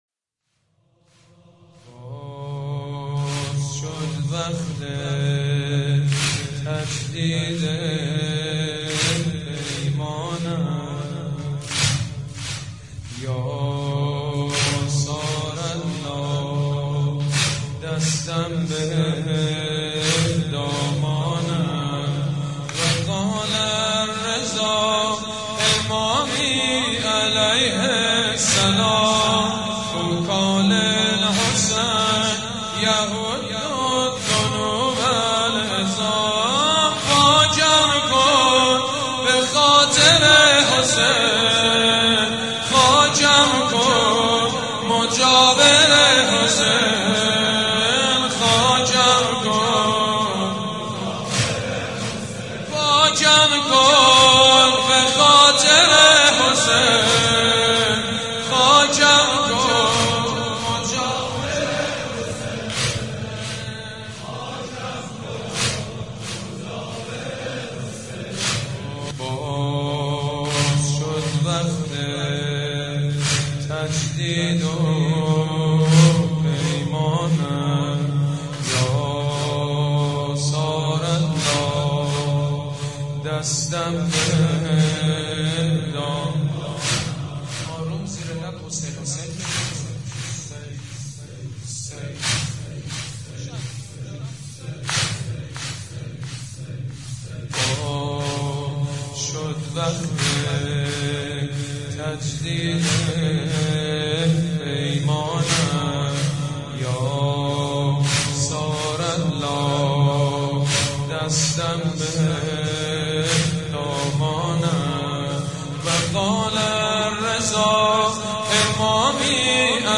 مداحی
نوحه